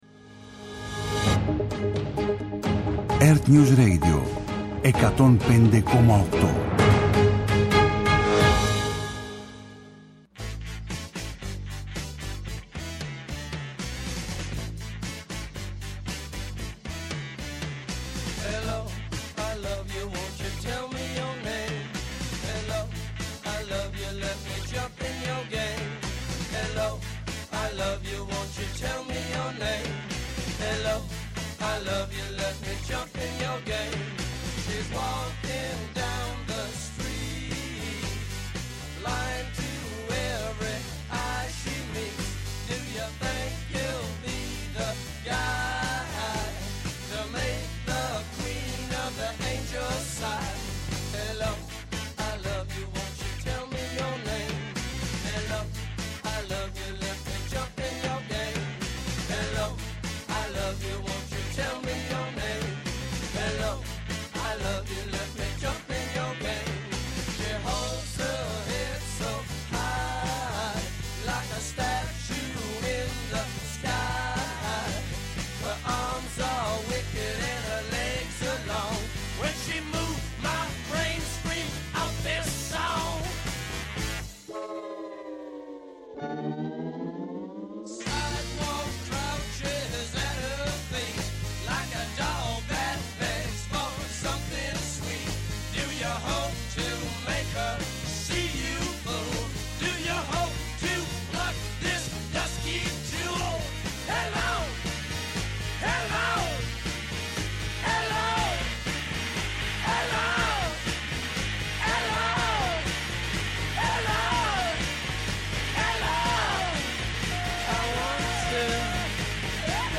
Με αναλύσεις, πρακτικές συμβουλές και συνεντεύξεις με πρωτοπόρους στην τεχνολογία και τη δημιουργικότητα, τα «Ψηφιακά Σάββατα» σας προετοιμάζει για το επόμενο update.